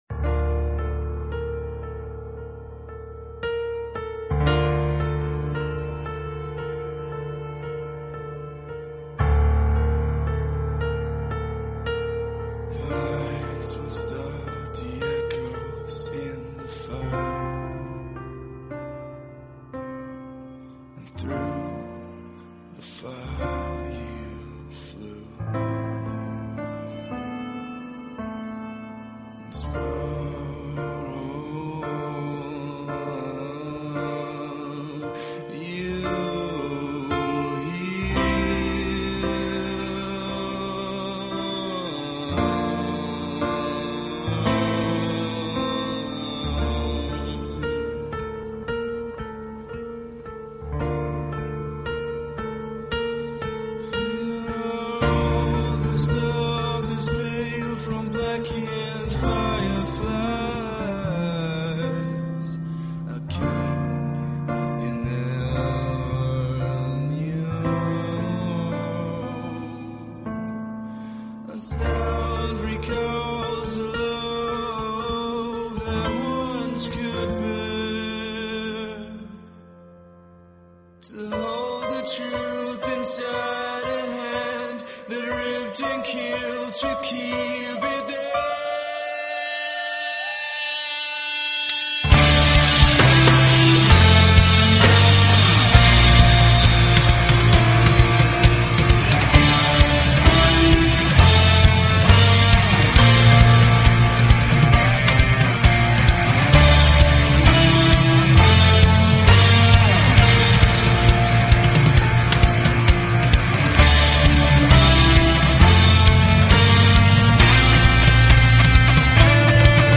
风格：后摇, 电子, 民谣